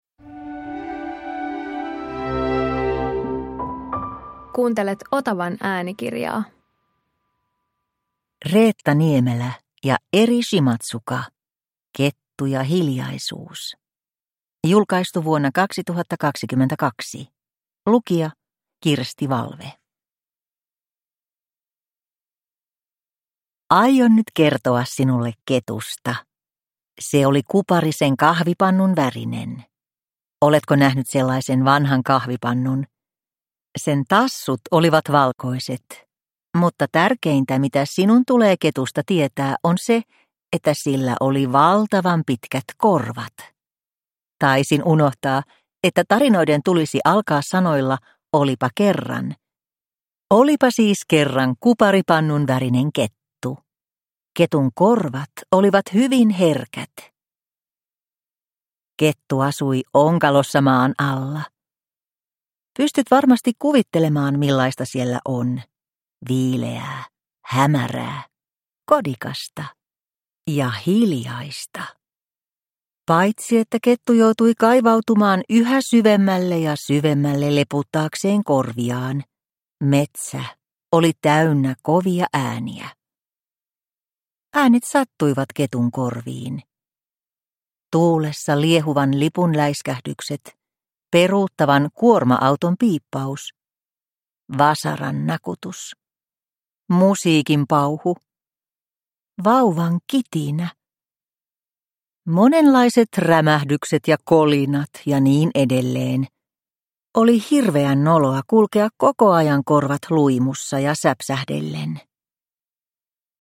Kettu ja hiljaisuus – Ljudbok